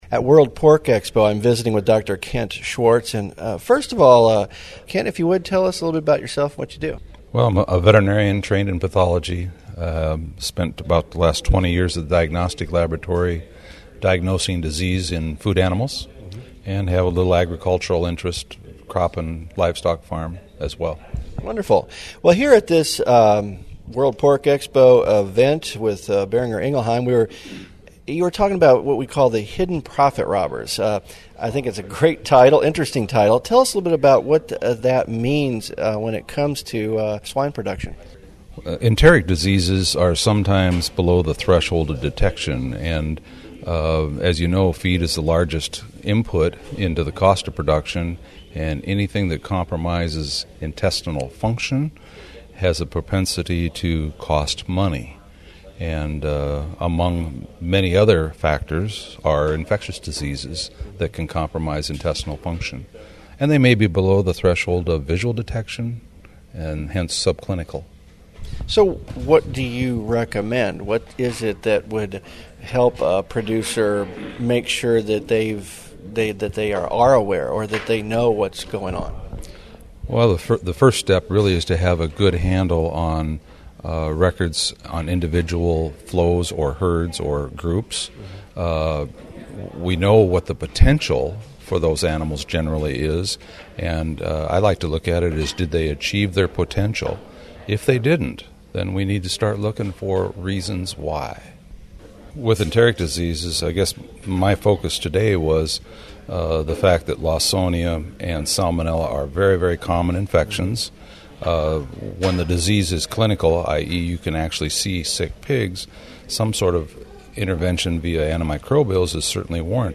Boehringer Ingelheim Vetmedica (BIVI) hosted a media event on the last day of World Pork Expo that focused on Keeping Food Safe and Profits Growing.